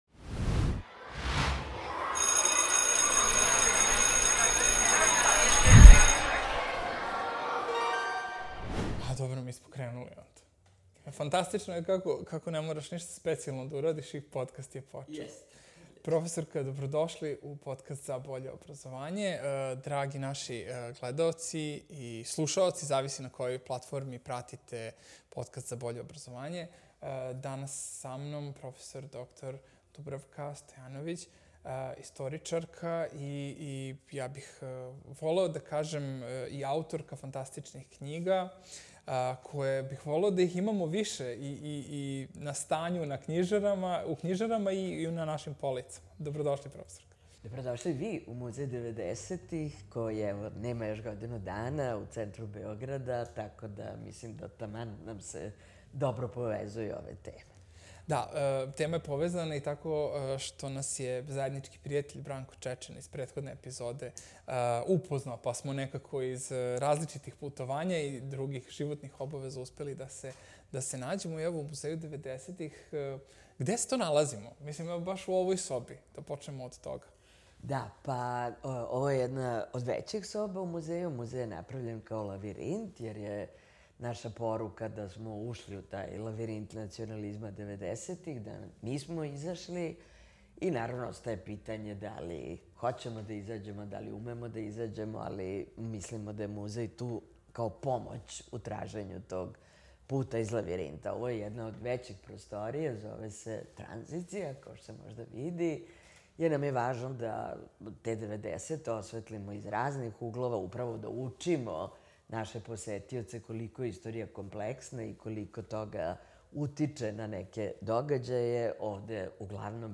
I kako istoriju približiti učenicima, a istovremeno očuvati kritički i analitički pristup? Razgovor smo snimili u Muzeju devedesetih, koji nam je ustupio prostor i doprineo autentičnosti ove epizode, a ovim putem im se zahvaljujemo na tome. Na temu podučavanja o istorijskim događajima gošća je bila: 💬 Dubravka Stojanović, istoričarka i profesorka na Filozofskom fakultetu u Beogradu